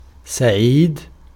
PronunciationArabic: [saˈʕiːd]